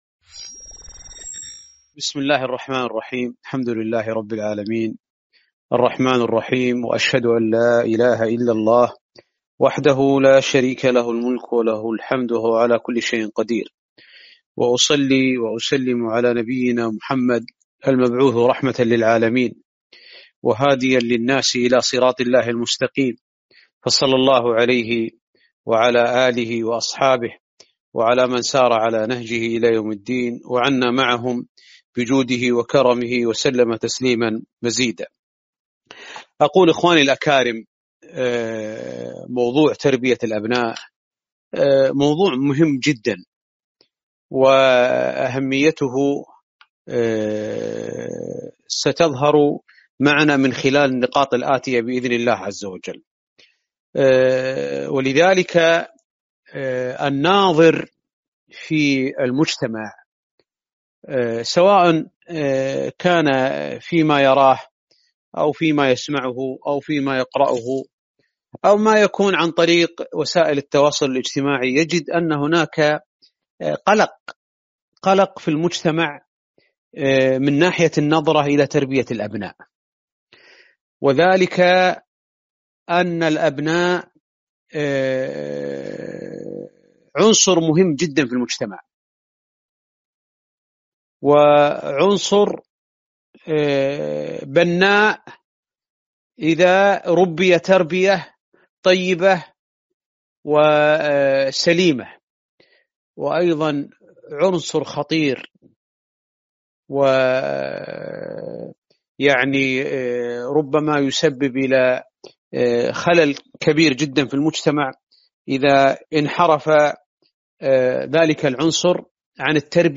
محاضرة - تربية الأبناء